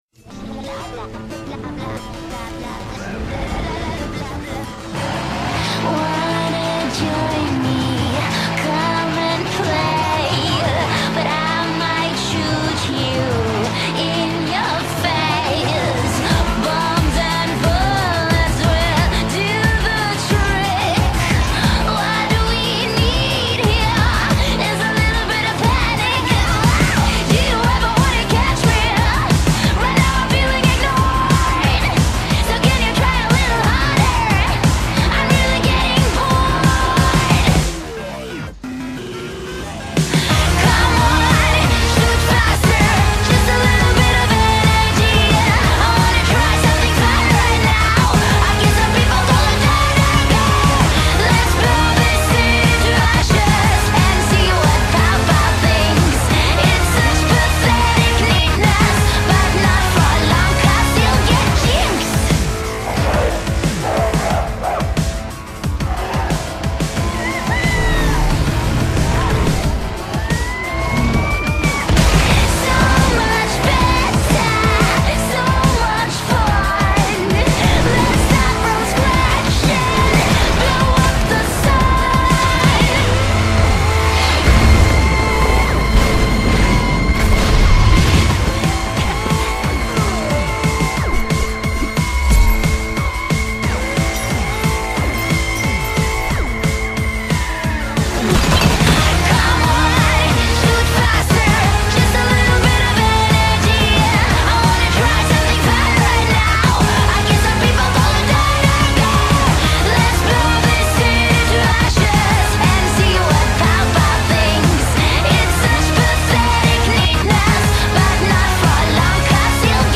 BPM90-180
Audio QualityCut From Video